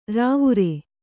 ♪ rāvure